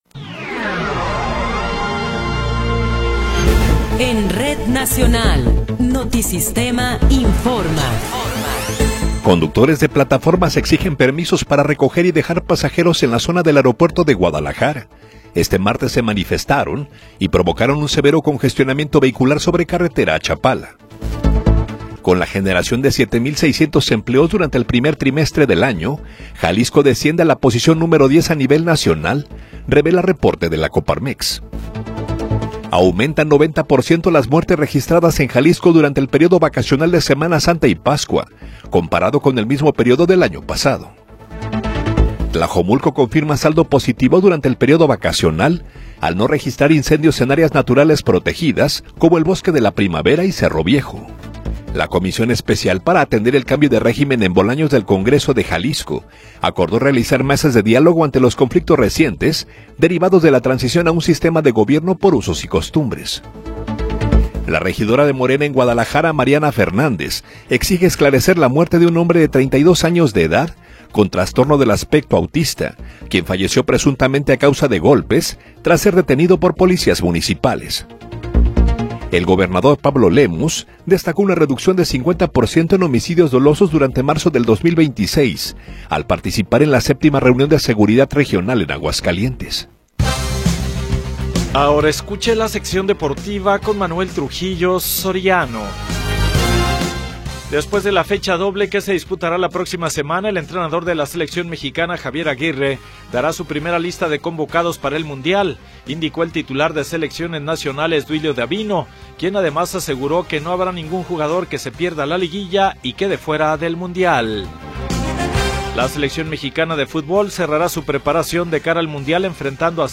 Noticiero 21 hrs. – 14 de Abril de 2026